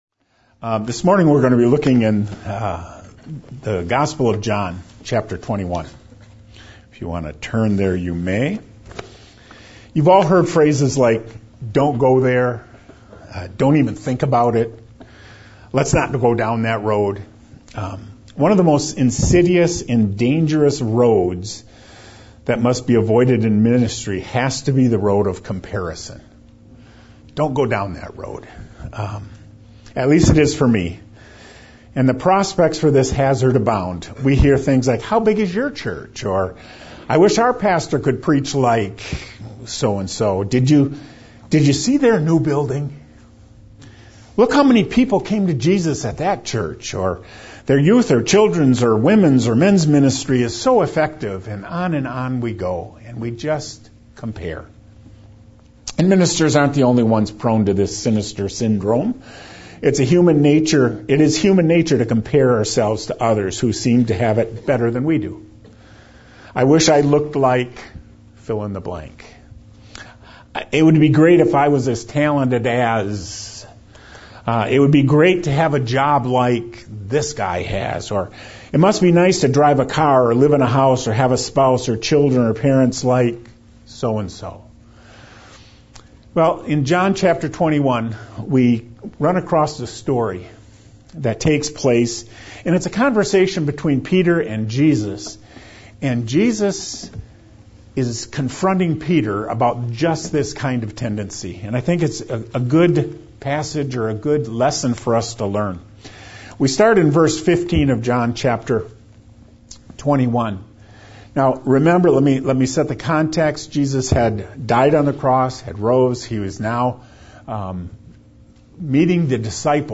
Sermons | Hartford Bible Church